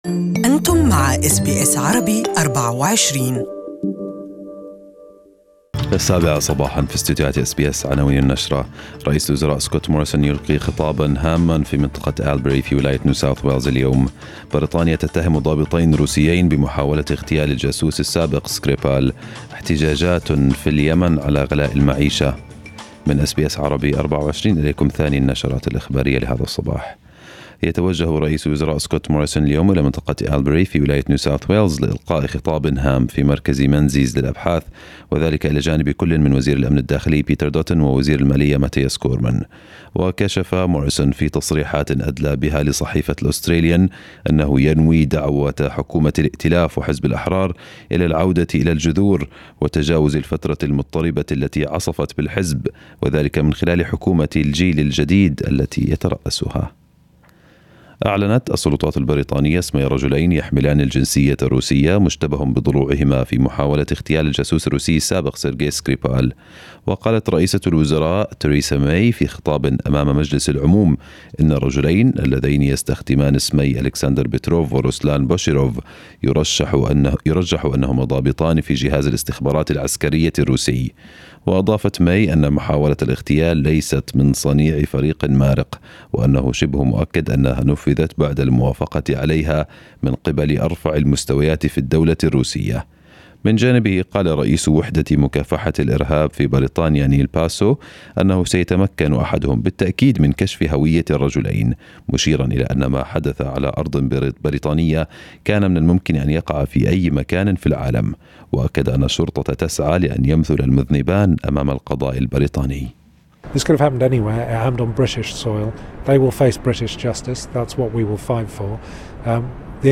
Listen to the full news bulletin in Arabic above.